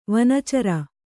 ♪ vana cara